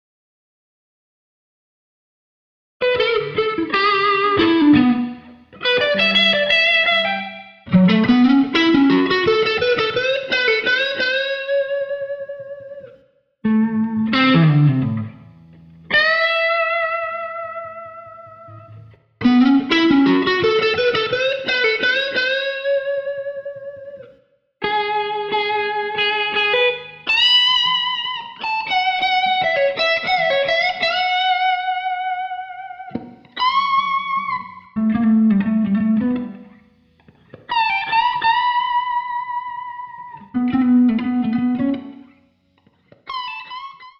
SoloGit_2    und dies ist der Klang der 2.
Beide Parts (und die Rhythmus-Parts) wurden mit der selben Gitarre aufgenommen - aber über unterschiedliche Amps eingespielt
SoloGit_2.wav